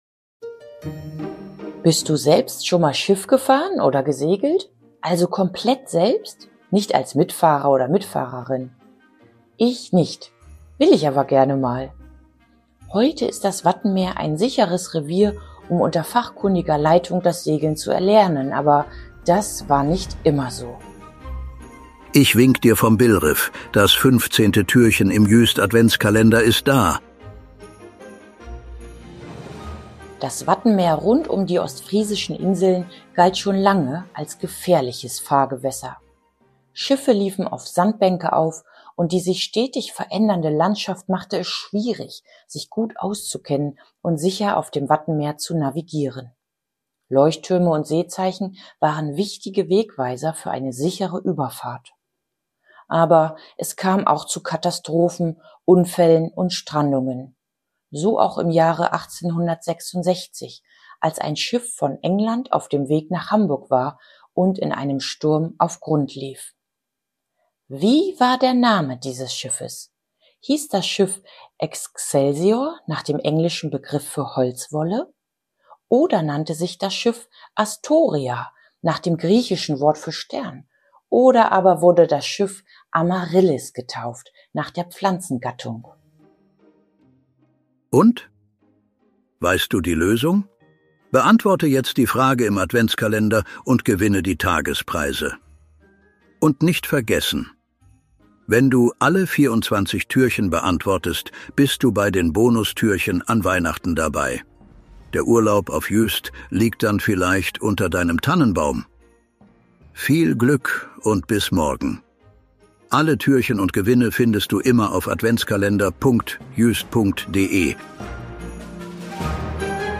guten Geistern der Insel Juist, die sich am Mikro abwechseln und